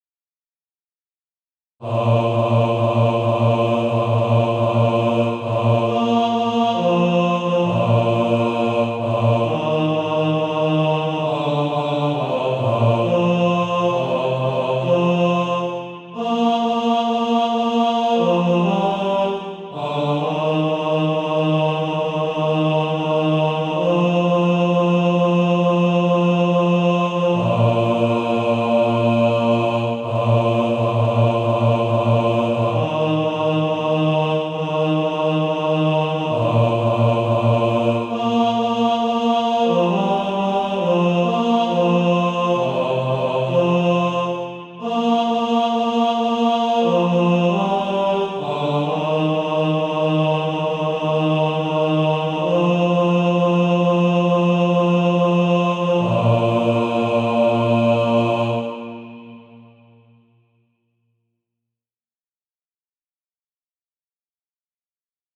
Bass Track.
Practice then with the Chord quietly in the background.